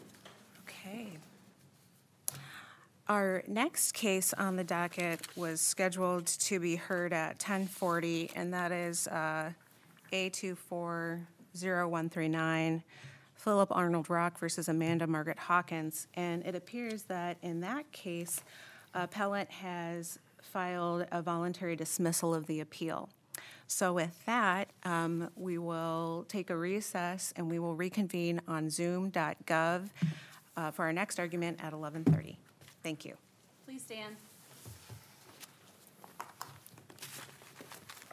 Minnesota Court of Appeals Oral Argument Audio Recording